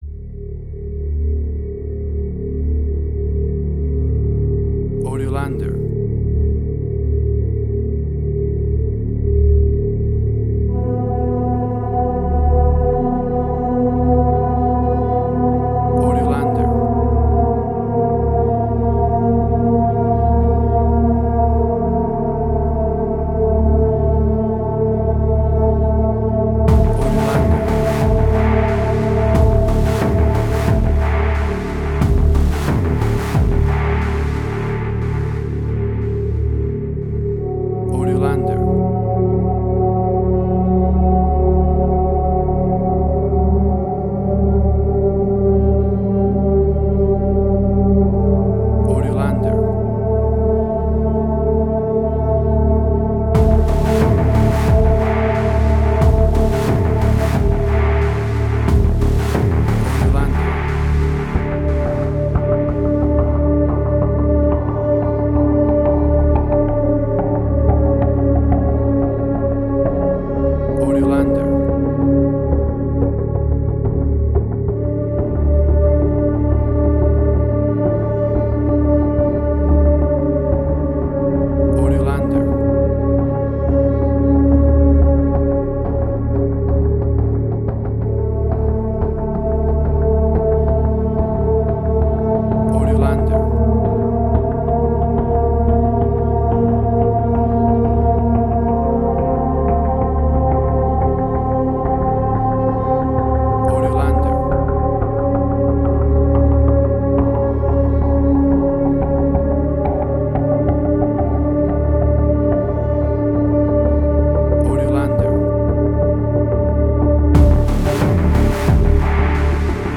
Suspense, Drama, Quirky, Emotional.
Tempo (BPM): 90